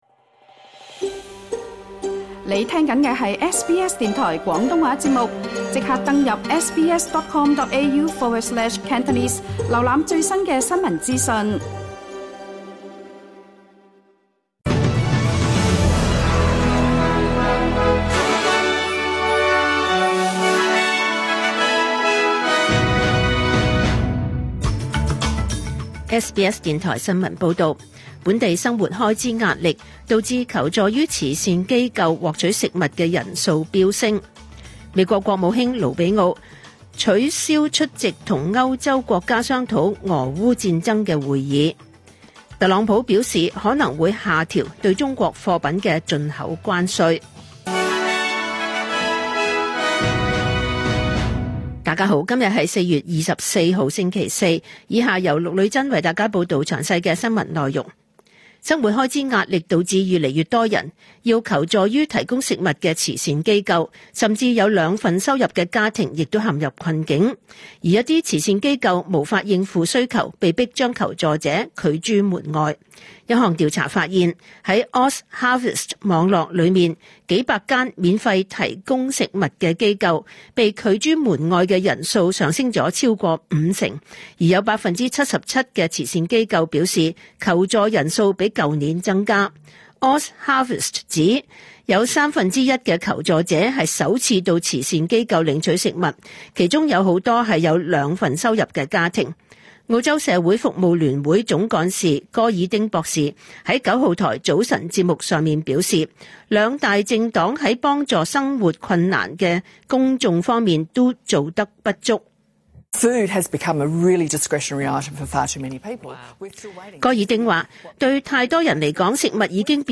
2025 年 4 月 24 日 SBS 廣東話節目詳盡早晨新聞報道。